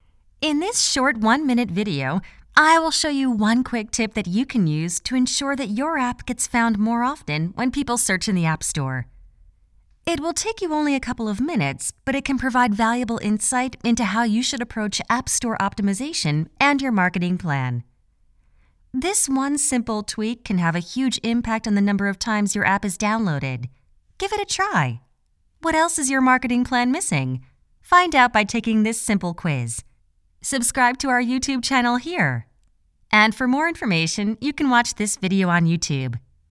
Female
Explainer Videos
Product Demonstration Bright